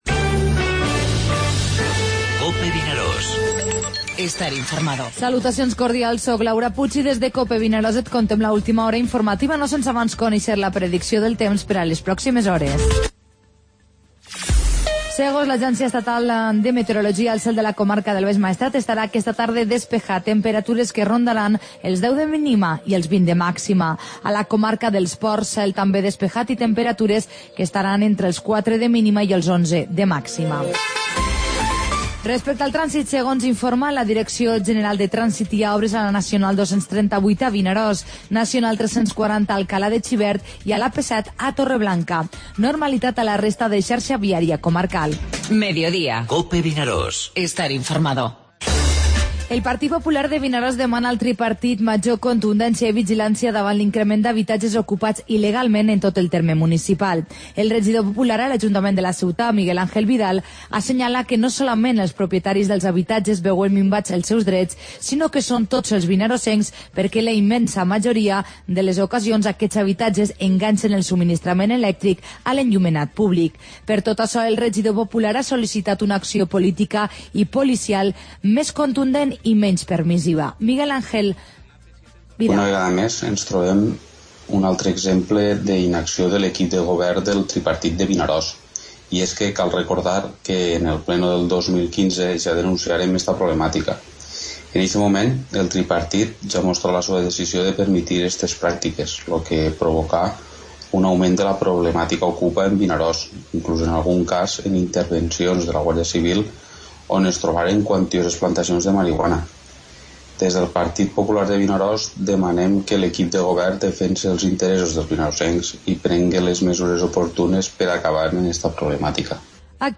Informativo Mediodía COPE al Maestrat (dimecres 9 de novembre)